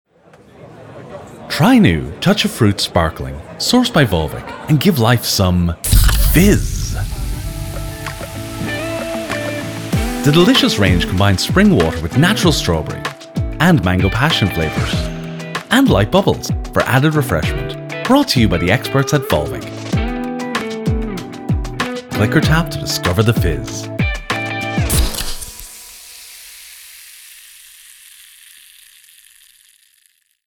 Every file is recorded to broadcast quality and delivered ready to use — no fuss.